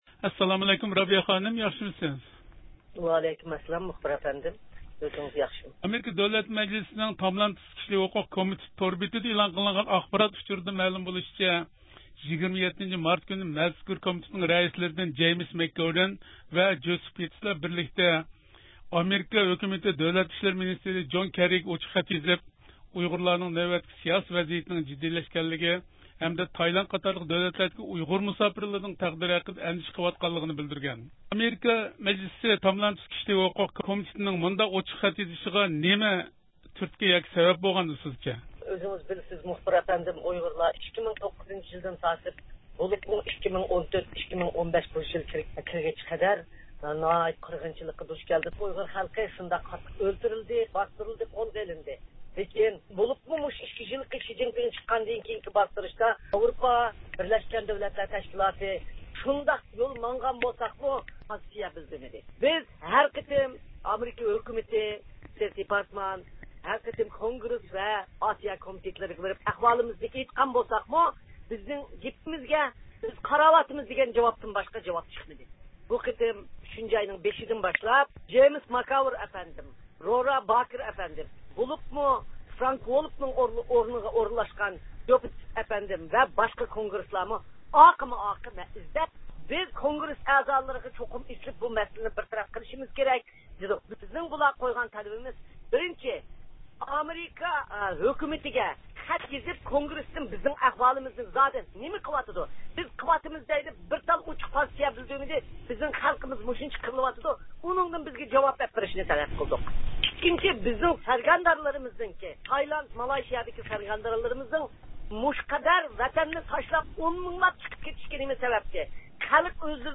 دۇنيا ئۇيغۇر قۇرۇلتىيىنىڭ رەئىسى رابىيە قادىر خانىم رادىيومىزنىڭ بۇ ھەقتىكى زىيارىتىنى قوبۇل قىلىپ، ئۆز قاراشلىرىنى ئوتتۇرىغا قويدى.